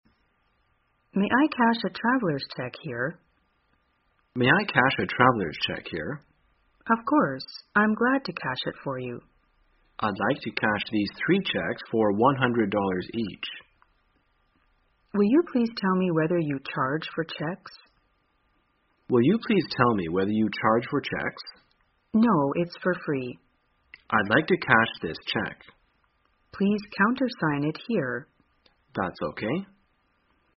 在线英语听力室生活口语天天说 第174期:怎样兑现支票的听力文件下载,《生活口语天天说》栏目将日常生活中最常用到的口语句型进行收集和重点讲解。真人发音配字幕帮助英语爱好者们练习听力并进行口语跟读。